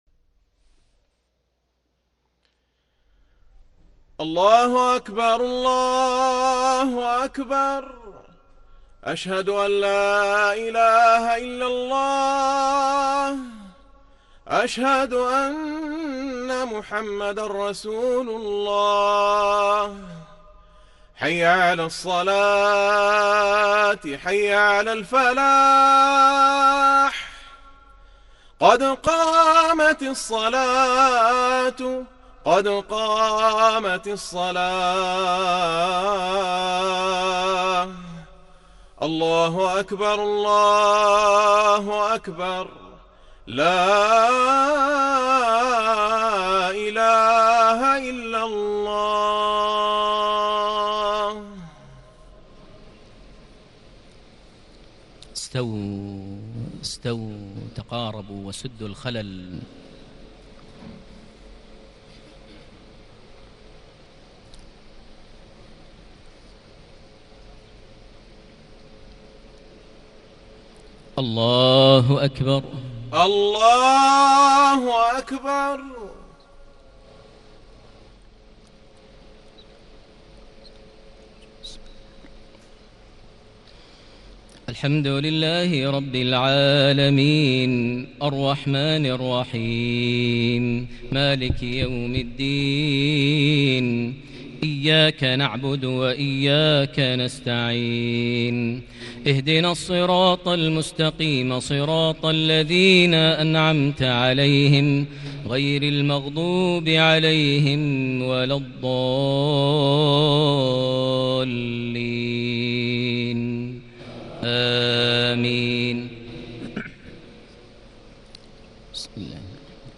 صلاة الجمعة 17 صفر 1440 سورتي الأعلى والغاشية > 1440 هـ > الفروض - تلاوات ماهر المعيقلي